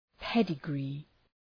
Προφορά
{‘pedə,gri:}